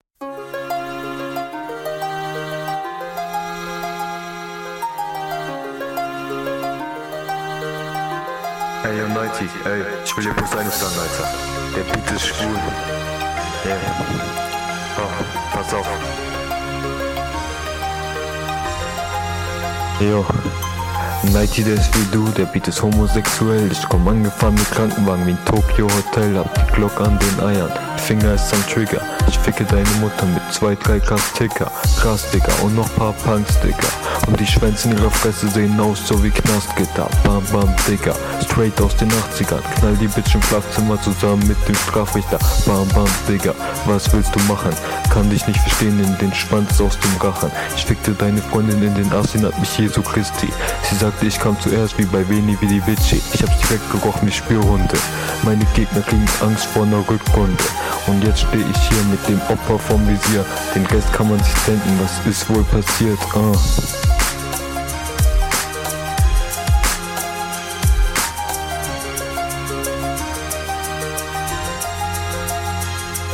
Flow: Klingt als hätt der das aufgenommen bevor er den Beat kannte.
Flow: Du bist auf dem Takt aber auch teilweise unverständlich.